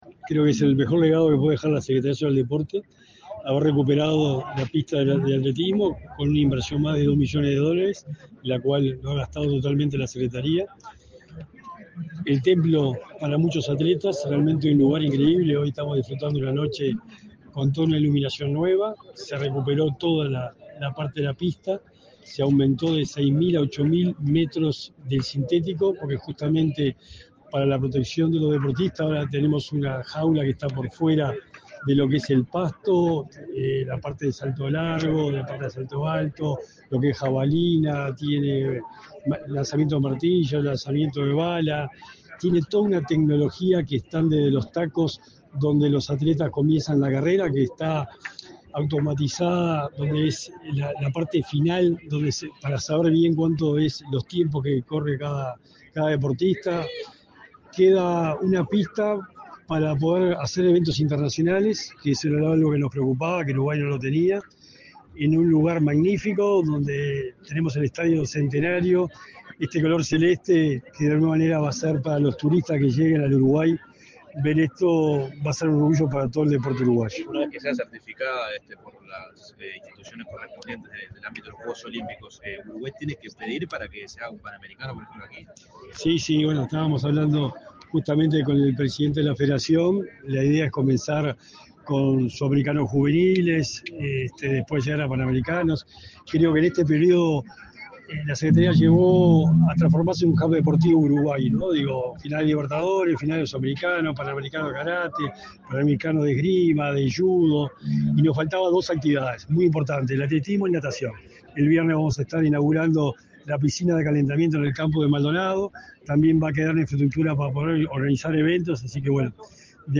Declaraciones a la prensa del secretario nacional del Deporte, Sebastián Bauzá
Declaraciones a la prensa del secretario nacional del Deporte, Sebastián Bauzá 18/02/2025 Compartir Facebook X Copiar enlace WhatsApp LinkedIn El presidente de la República, Luis Lacalle Pou, recorrió, este 18 de febrero, las obras que se realizan en la Pista de Atletismo Darwin Piñeyrúa y participó en el encendido de las luces. Tras el evento, el secretario nacional del Deporte, Sebastián Bauzá, realizó declaraciones a la prensa.